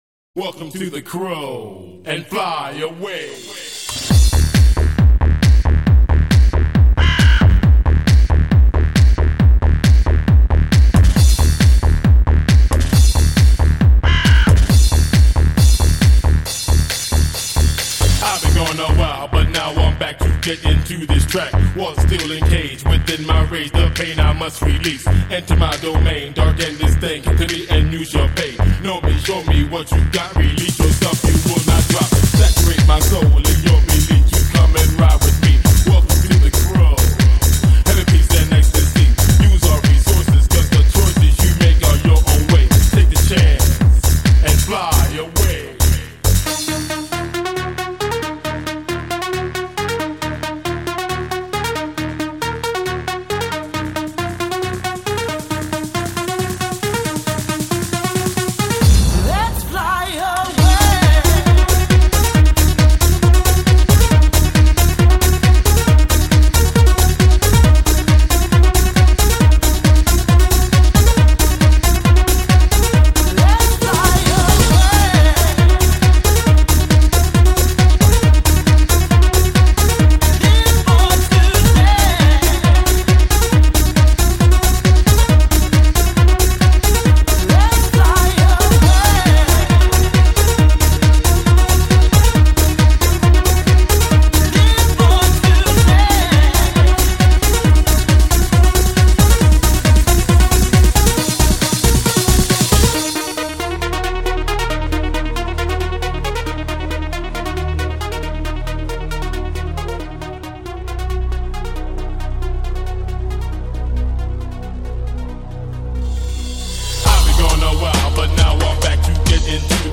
Жанр: Trance, Euro House